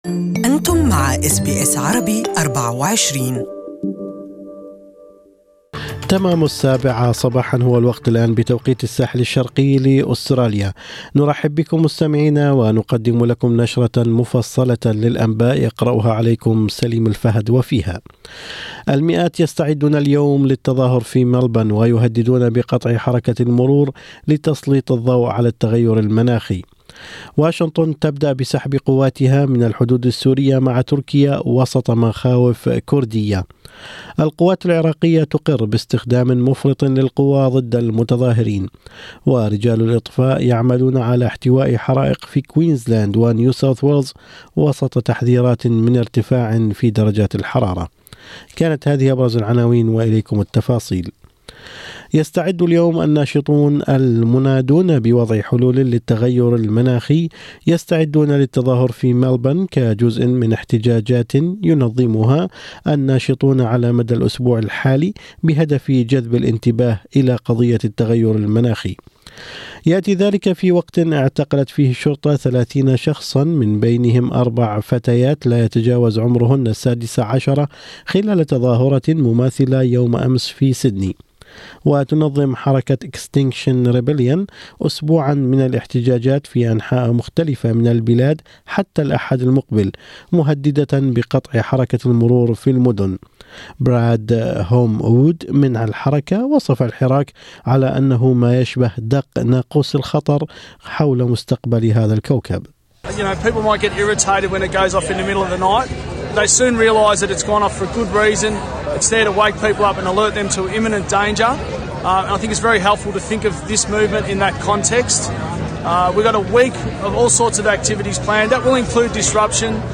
أخبار الصباح: متظاهرو التغير المناخي يسعون لعرقلة السير في مراكز المدن اليوم